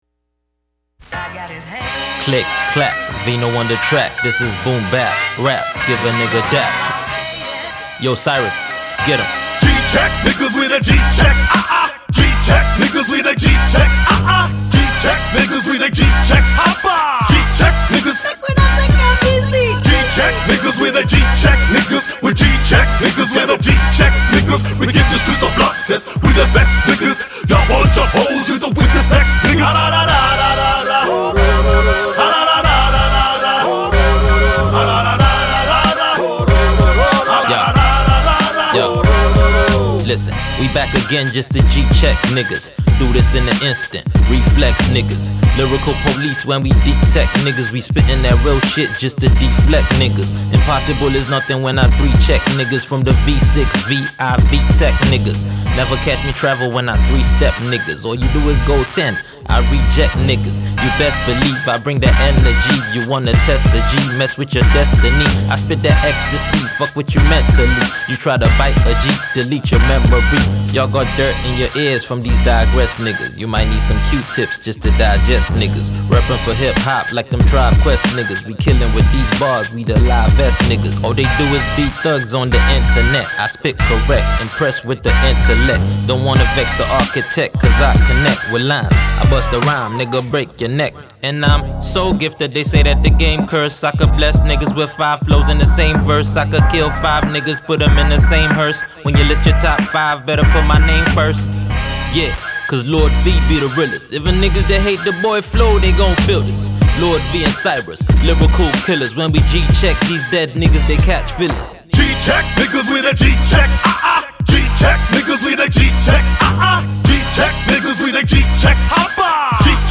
HipHop Cut